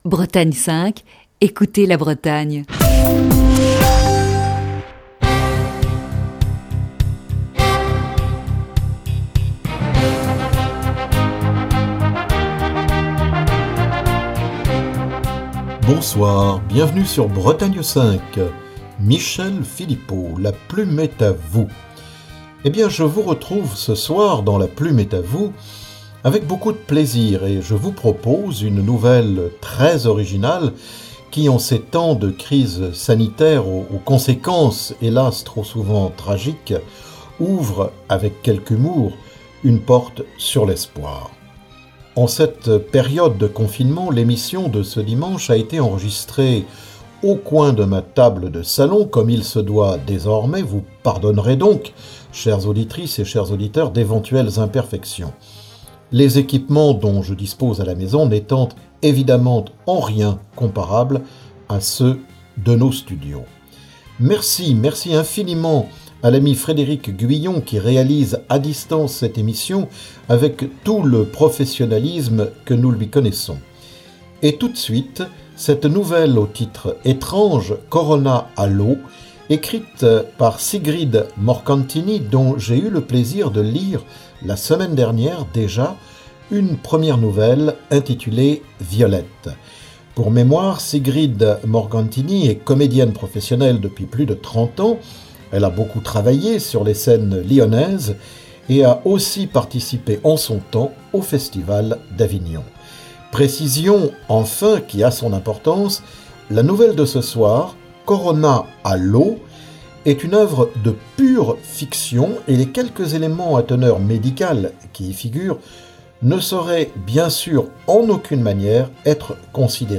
Bretagne 5 vit à l'heure du confinement